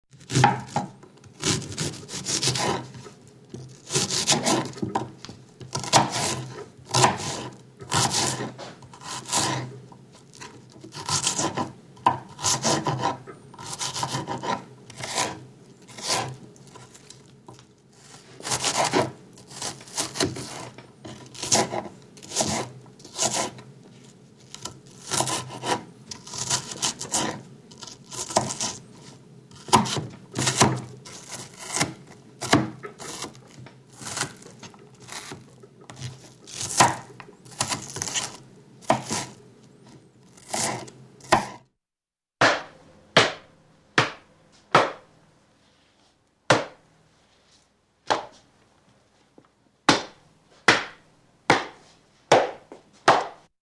Звуки ножа
Ножом шинкуют капусту